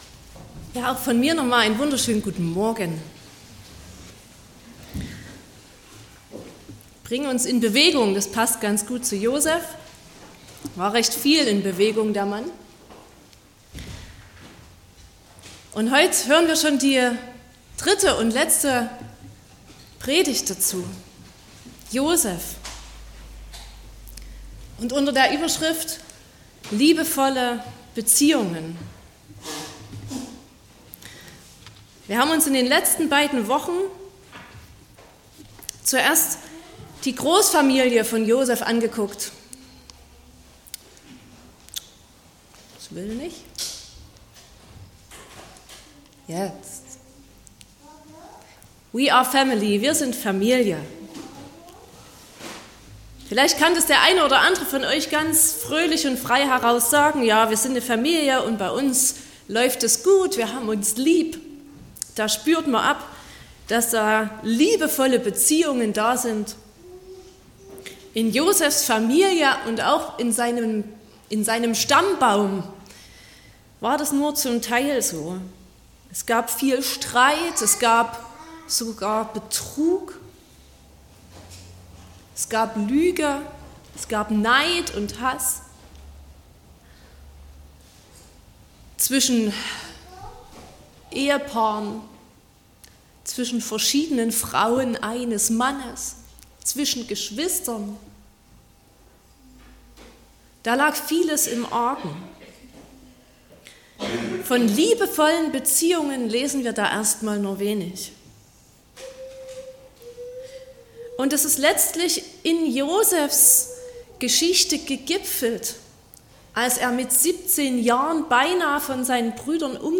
23.03.2025 – Gottesdienst
Predigt (Audio): 2025-03-23_Josef_-_Teil_3_3____Geheilt_und_versoehnt.mp3 (30,9 MB)